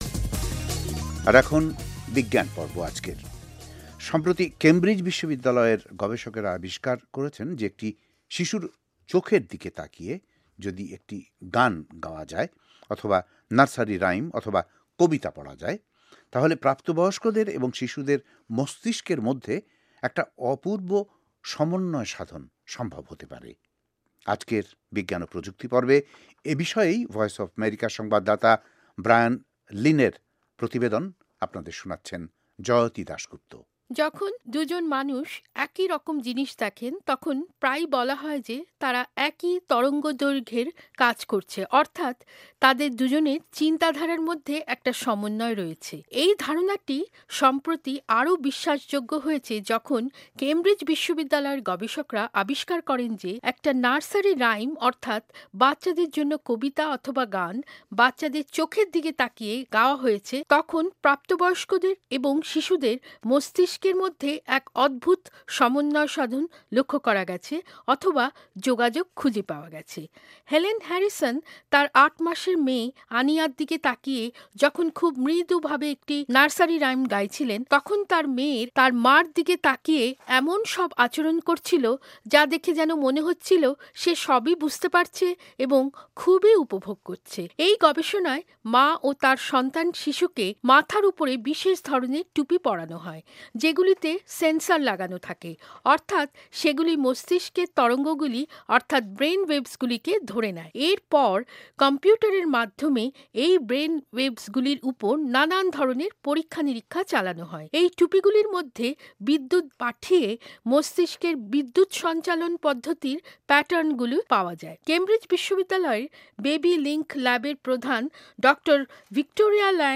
প্রতিবেদনটি